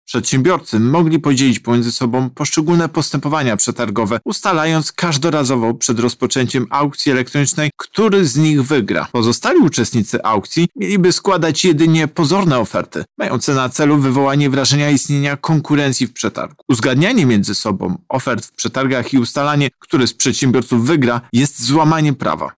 O tym, jak wyglądał proceder mówi Tomasz Chróstny, prezes UOKiK